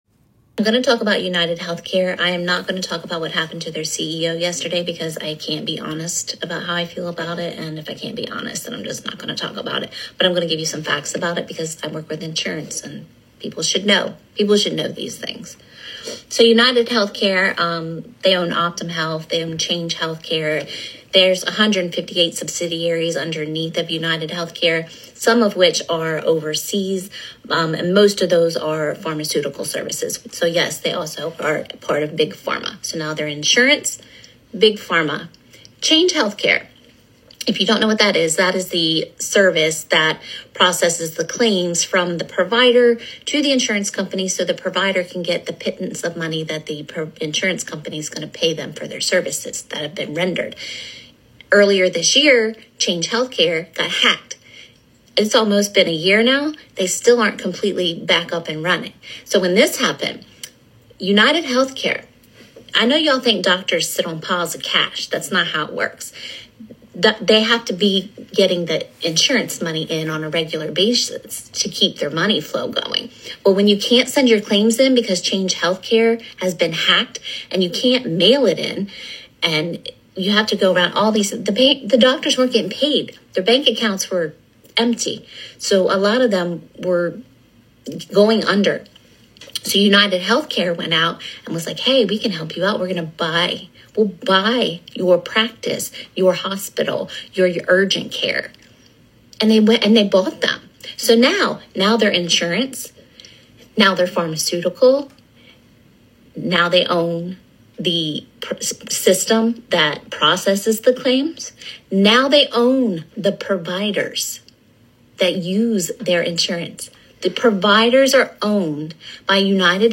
An insurance worker from United Healthcare talks about how their monopoly is being used to increase their profits and rip off the public.